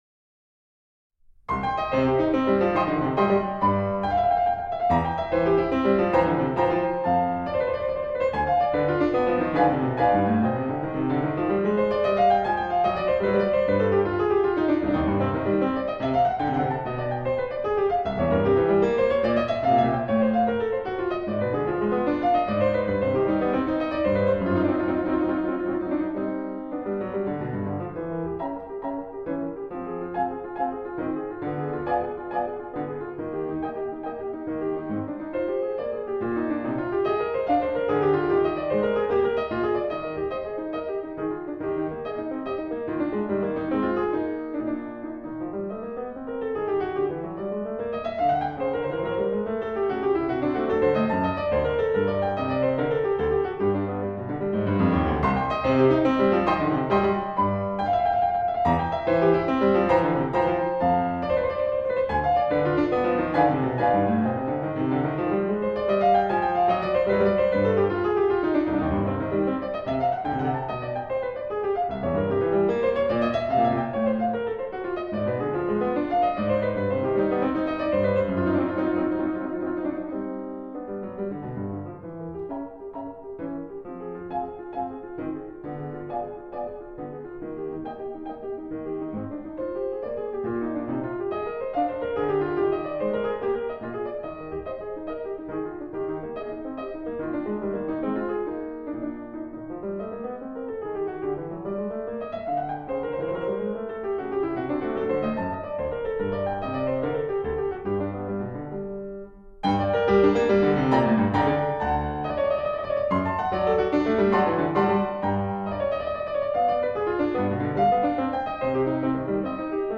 Group: Instrumental